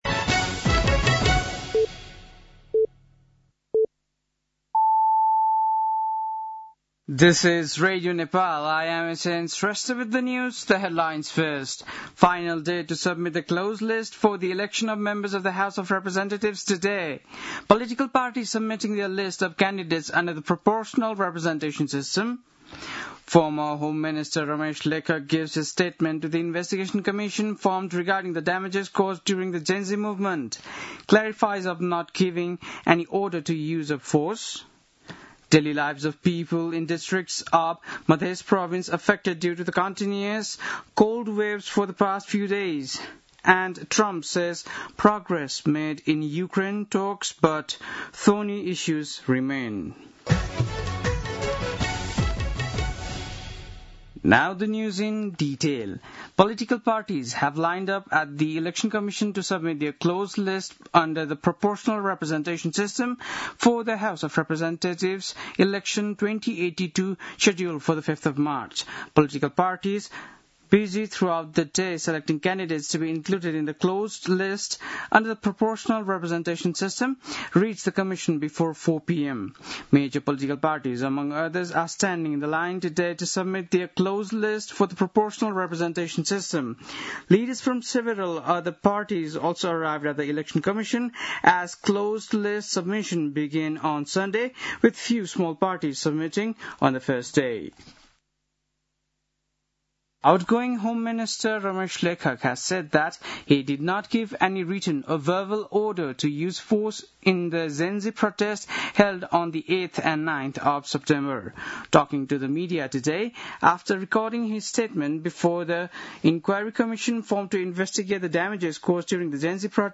बेलुकी ८ बजेको अङ्ग्रेजी समाचार : १४ पुष , २०८२
8-pm-english-news-9-14.mp3